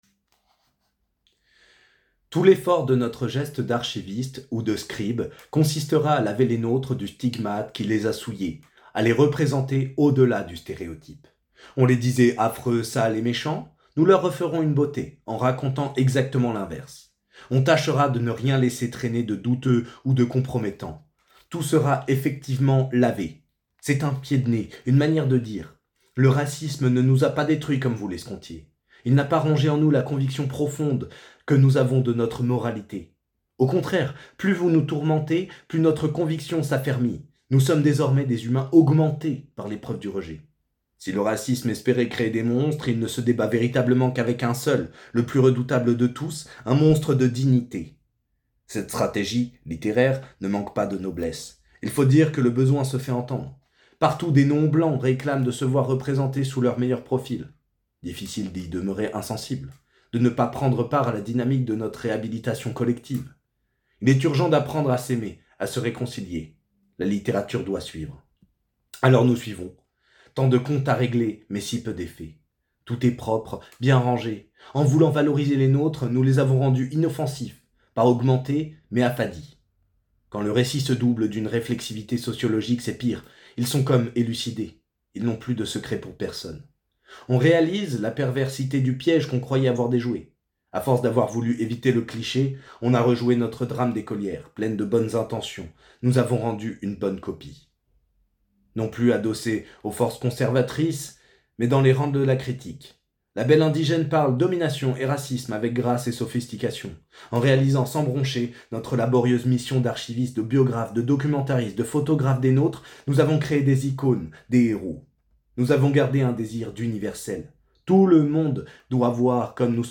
Lecture Rester barbare Louisa Yousfi